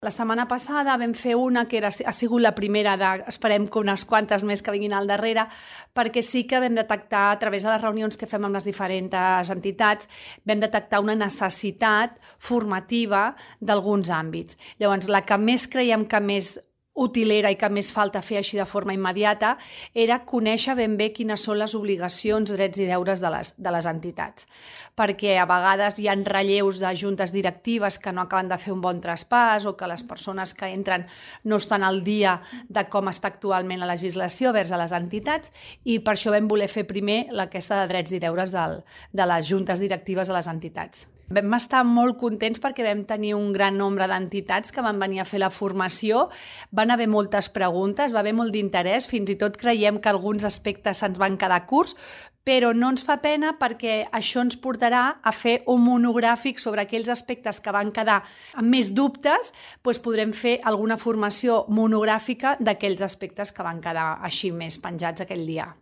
Àngels Soria, regidora de Teixit Associatiu